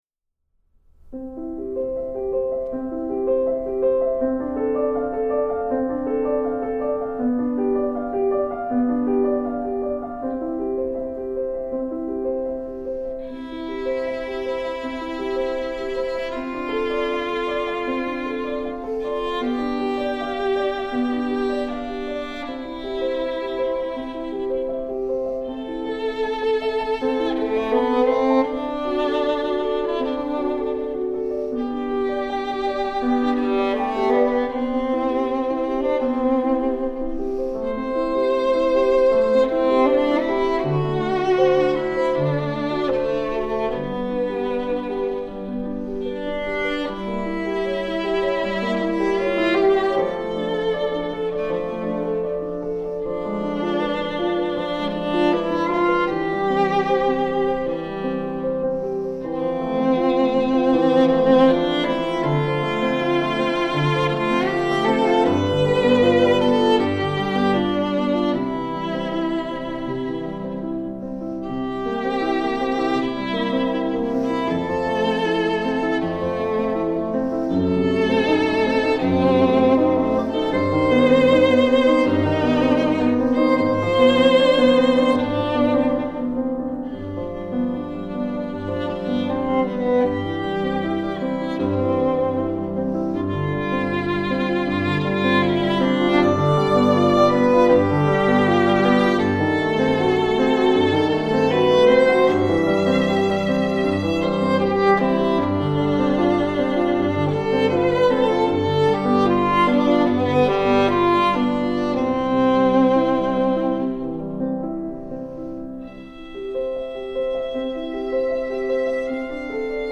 Voicing: Violin Collection